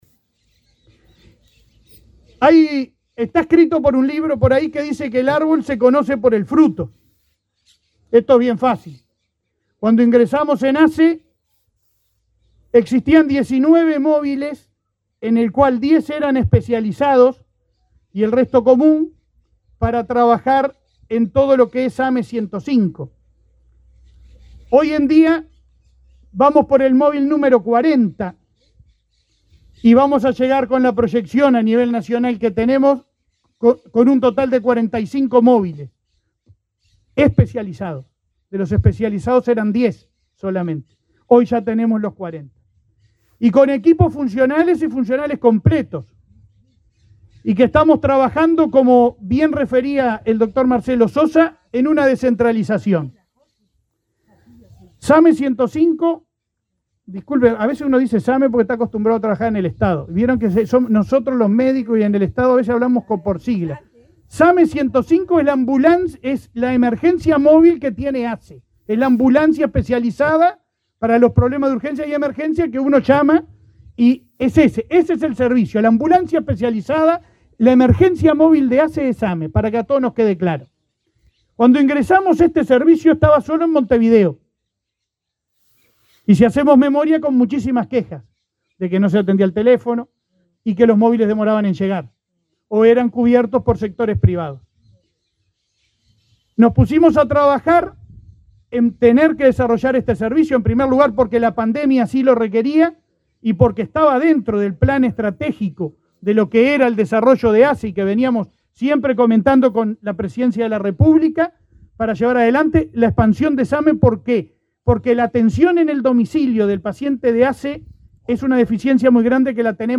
Palabras del presidente de ASSE, Leonardo Cipriani
El presidente de ASSE, Leonardo Cipriani, participó este jueves 26 en la inauguración de la base del Sistema de Atención Médica de Emergencia (SAME